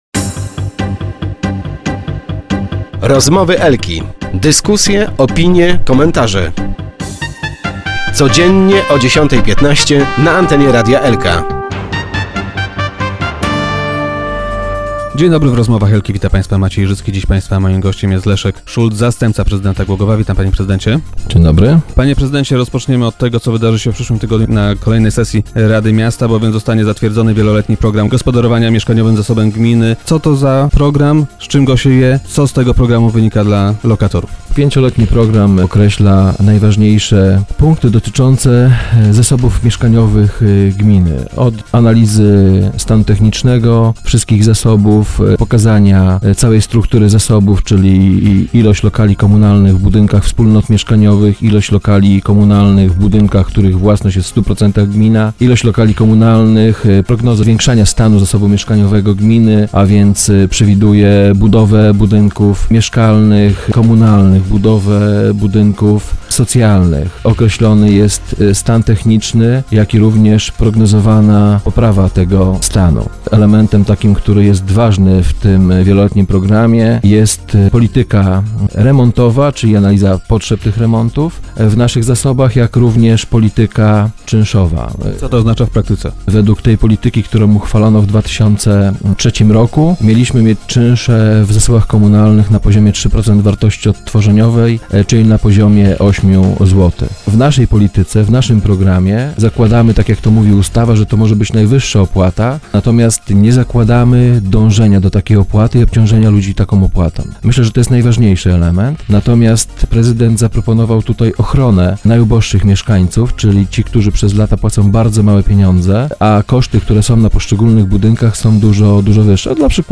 - Ważnym elementem tego programu jest polityka remontowa oraz polityka czynszowa. Jeśli chodzi o tą ostatnią, to w naszym programie nie zakładamy dążenia do maksymalnych, dopuszczonych prawem, opłat czynszowych. Proponujemy natomiast ochronę najuboższych mieszkańców. Tych, którzy w tej chwili płacą małe pieniądze, natomiast koszty zarządzania i utrzymania ich budynków są zdecydowanie wyższe - mówił Leszek Szulc, który był dziś gościem Rozmów Elki.